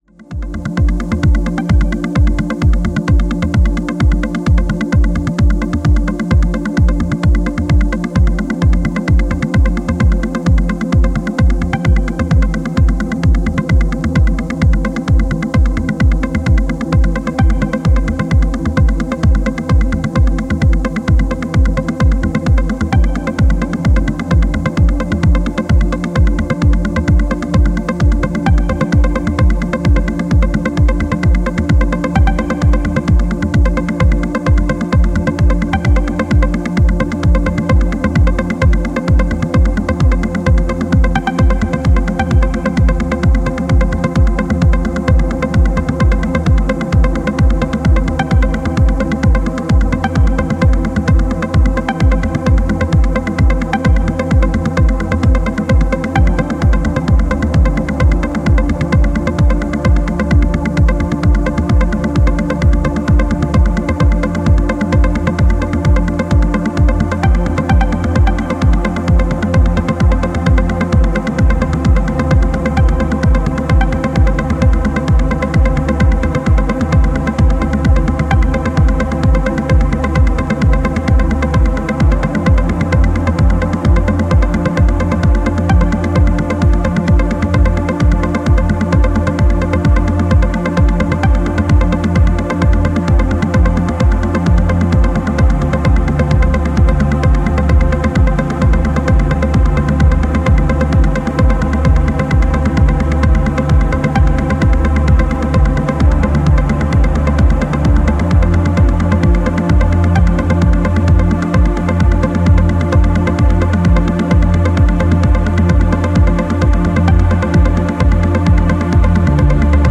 柔らかく弾かれるハイピッチのストリングスリフの繊細さが印象的な